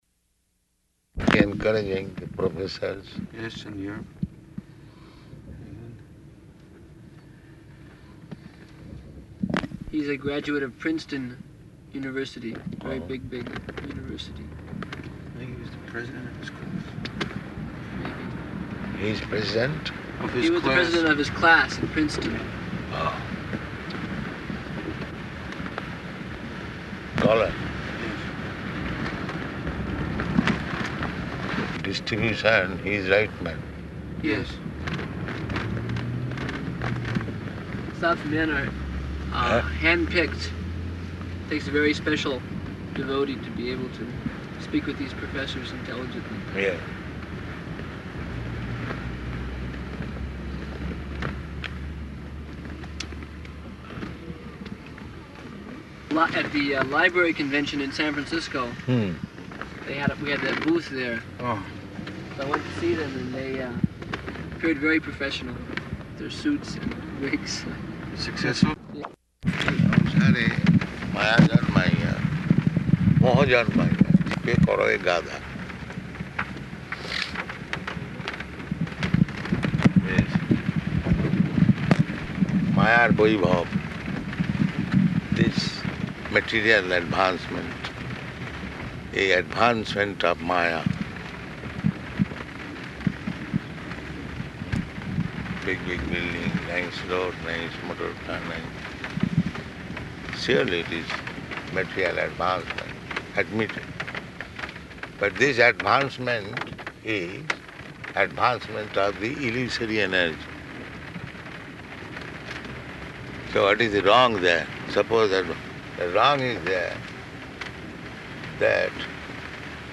Morning Walk --:-- --:-- Type: Walk Dated: July 5th 1975 Location: Chicago Audio file: 750705MW.CHI.mp3 [in car] Prabhupāda: ...encouraging the professors.